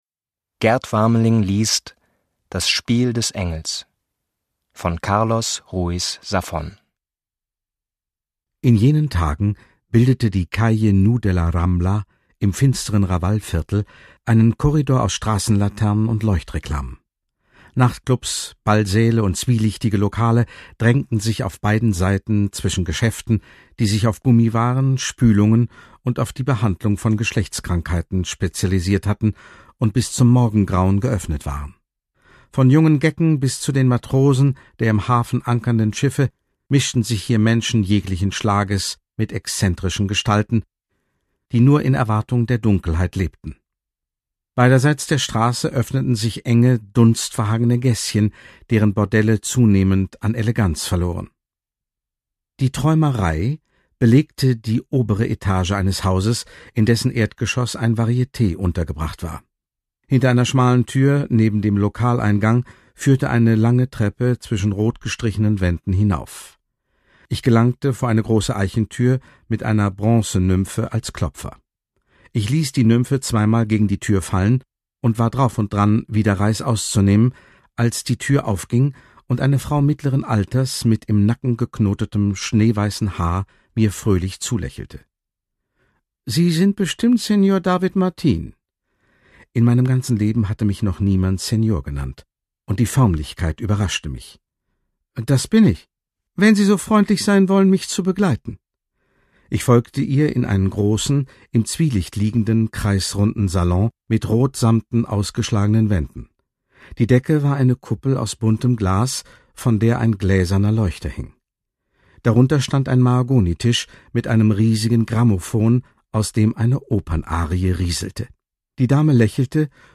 Gerd Wameling (Sprecher)
2017 | 2. Auflage, Gekürzte Ausgabe